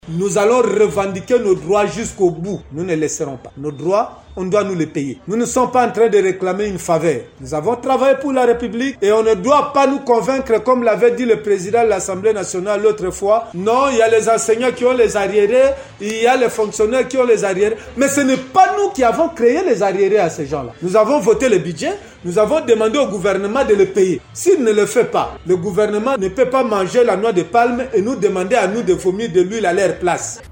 Selon Willy Bolio, ayant pris la parole au nom de ses collègues, leur revendication n'est pas une faveur mais plutôt un droit reconnu par les textes qui régissent le fonctionnement des institutions du pays :